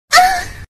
Anime Ahh